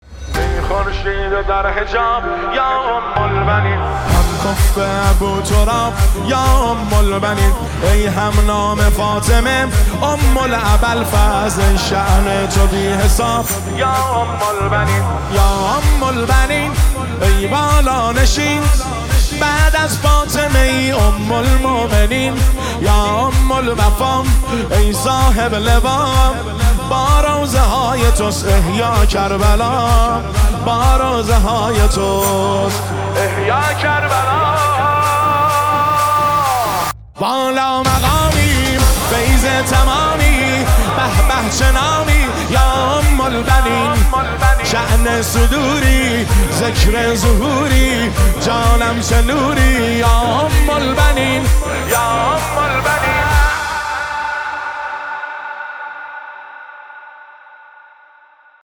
زمینه ، نماهنگ  شهادت حضرت ام البنین (س) 1404
هیئت حضرت ابوالفضل آران و بیدگل